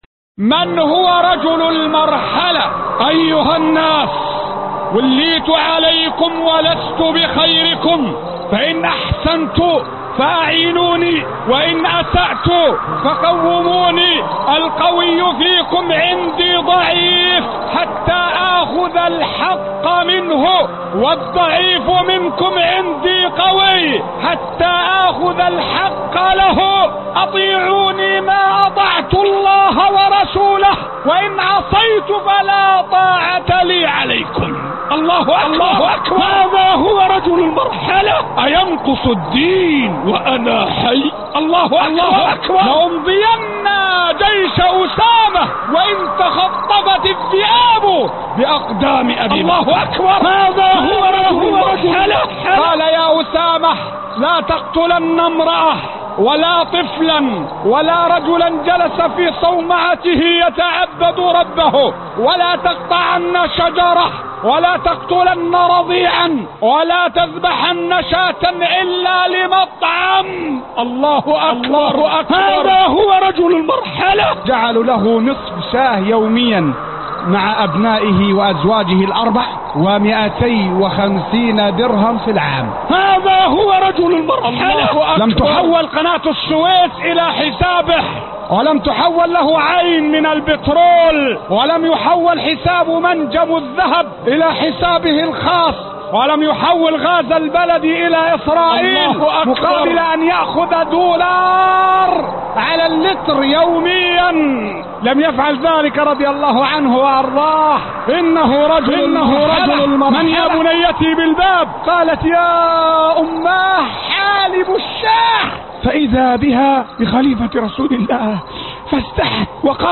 رجل المرحلة - خطب الجمعة